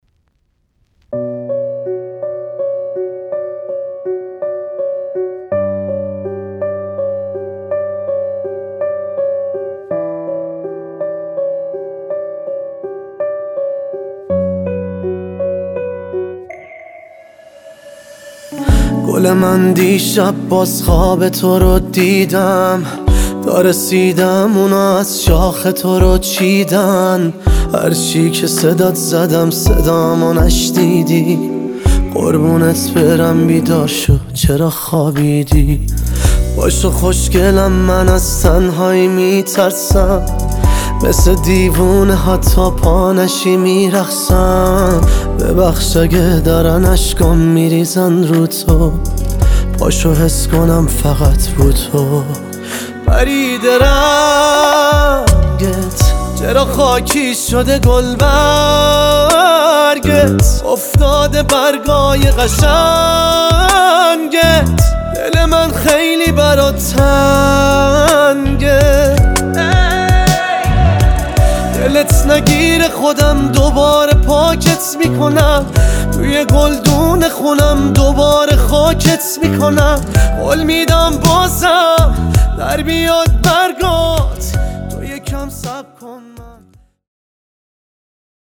آهنگ غمگین جدید